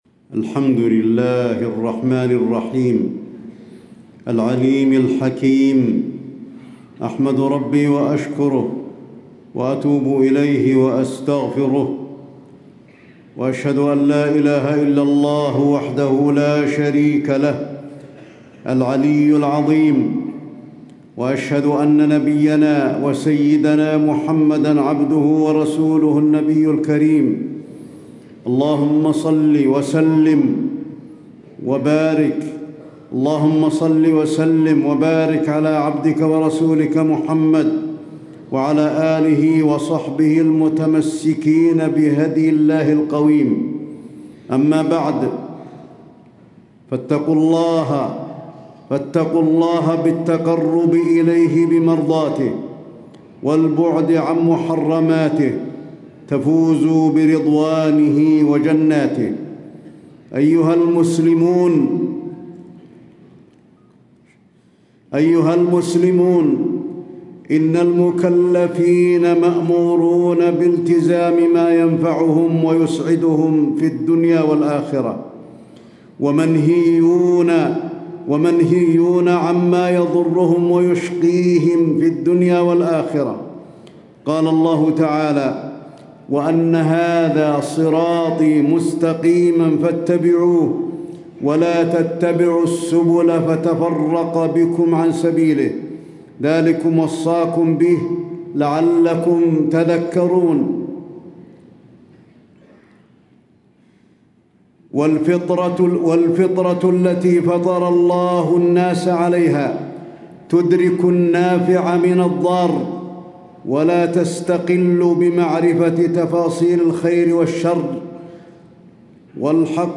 تاريخ النشر ١٩ رجب ١٤٣٦ هـ المكان: المسجد النبوي الشيخ: فضيلة الشيخ د. علي بن عبدالرحمن الحذيفي فضيلة الشيخ د. علي بن عبدالرحمن الحذيفي البدع كفران لنعمة الإسلام